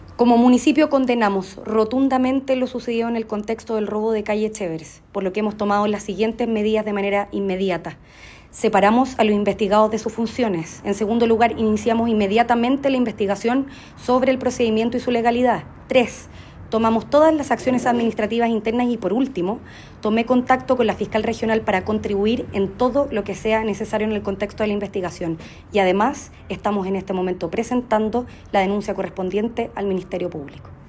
alcaldesa-medidas-por-patrulleros-.mp3